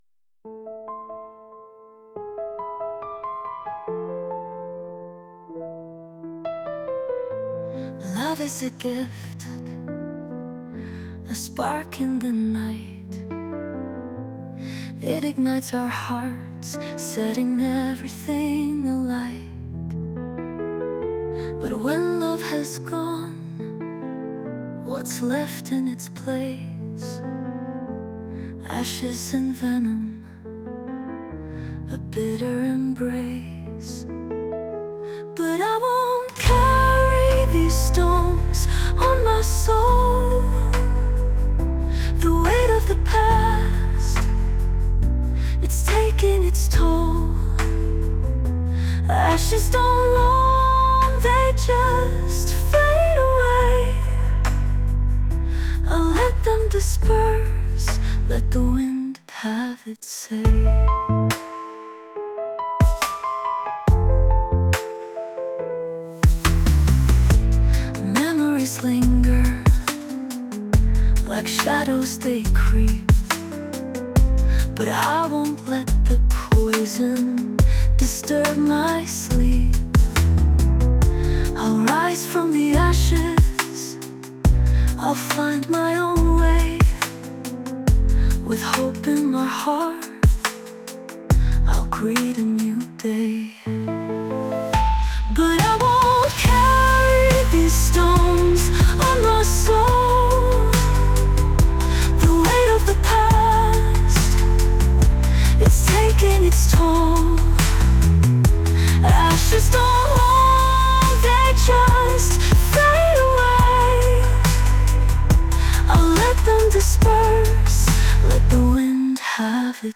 Vocals & Artwork